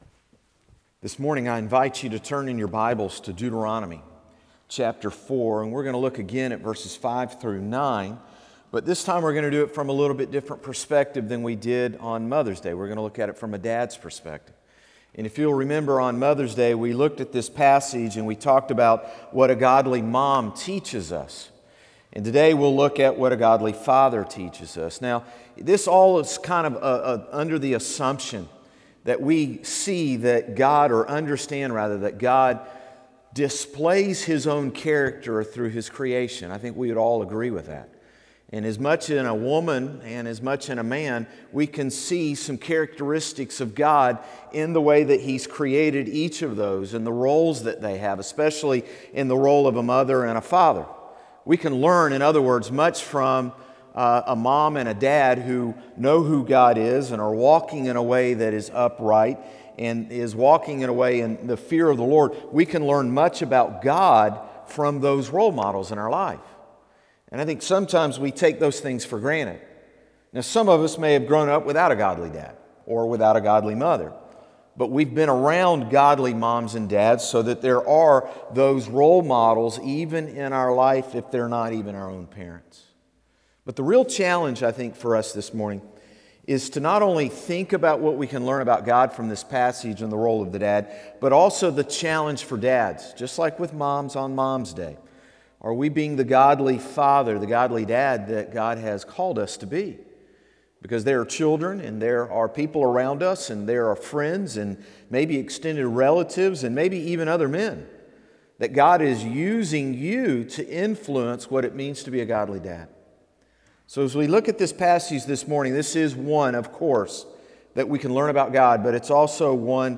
Sermons - Concord Baptist Church
Morning-Service-6-20-21.mp3